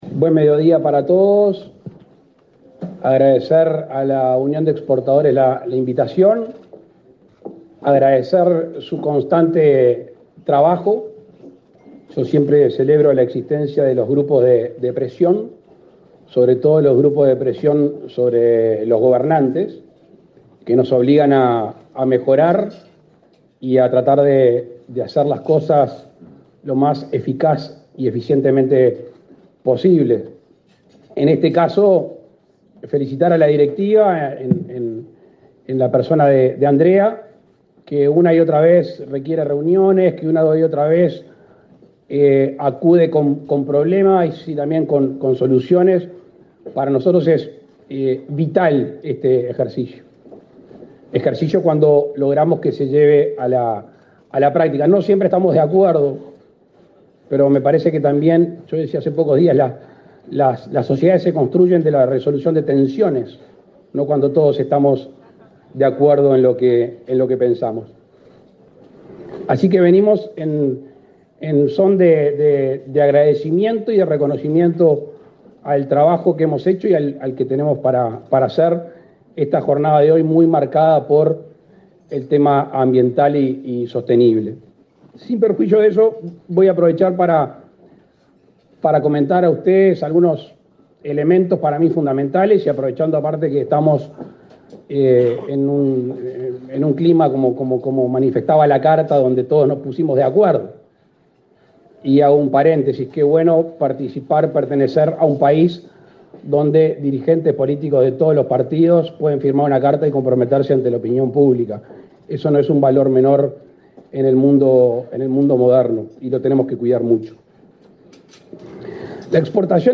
Palabras del presidente de la República, Luis Lacalle Pou
Con la presencia del presidente de la República se realizó, este 26 de julio, un almuerzo empresarial organizado por el Banco República y la Unión de